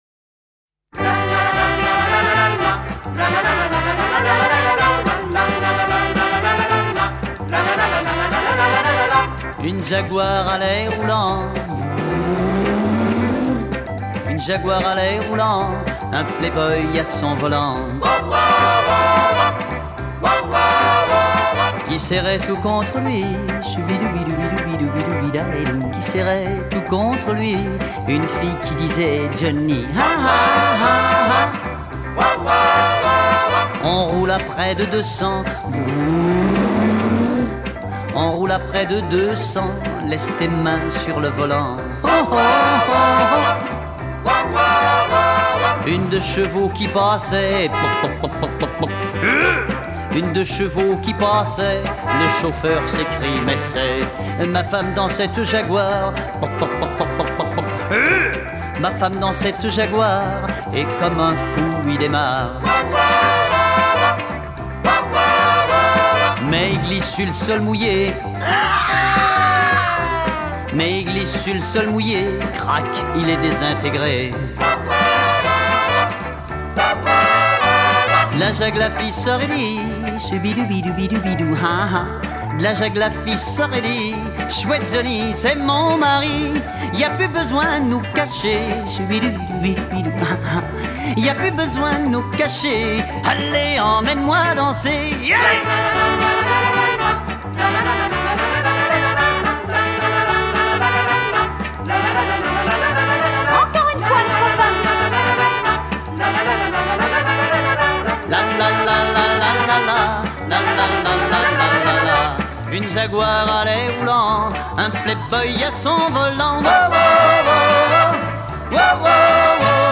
» ont été remplacés par un son de moteur ronronnant
yéyé